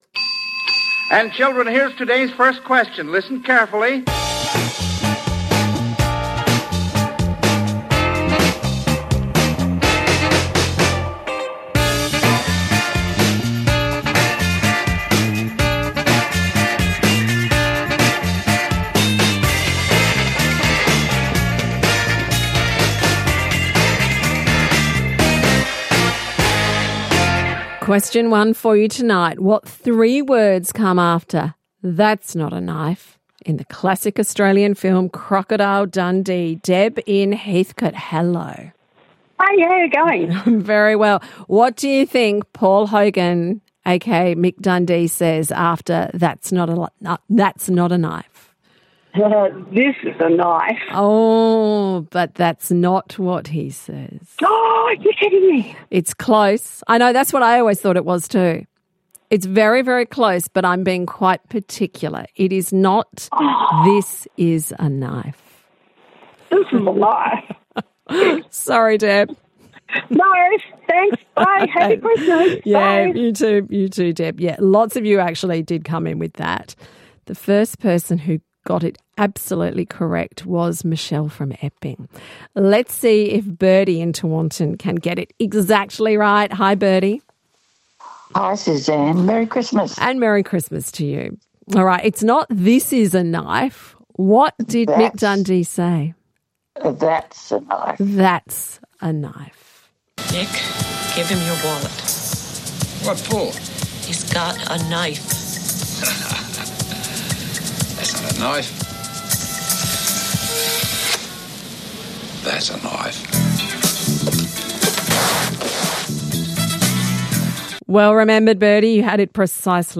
Are you into your trivia? Calling all connoisseurs of cryptic to the ONLY quiz played live, all around Australia...